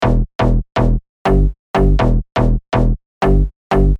Коммерческий дип/поп бас
Никак не нарулю этот на вид простой бас. Прошу вашей помощи Вырезал пару нот из одного трека, прикрепил.